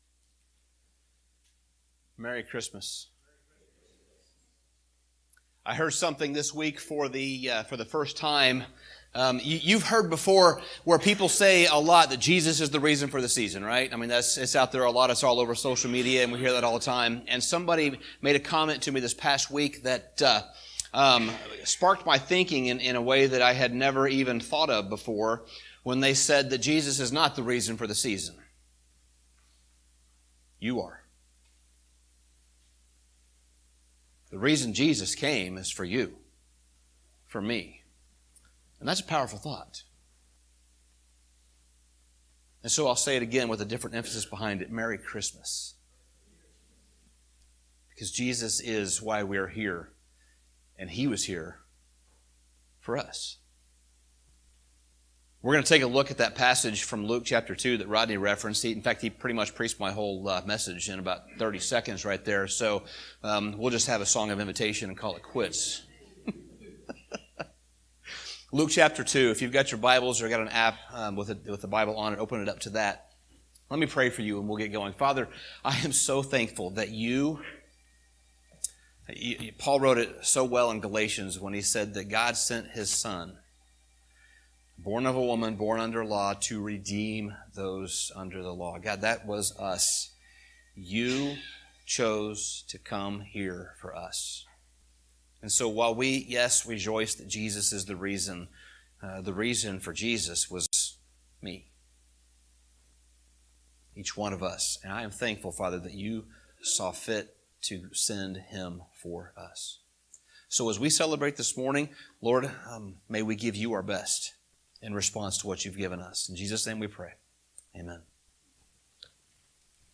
Sermon Summary This Christmas Sunday, we take a look at the angels' announcement to the shepherds in the fields.